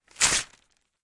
08 Rasgar papel 2
描述：Sound of paper being torn apart three times.
标签： apart ripping tearing paper rip tear
声道立体声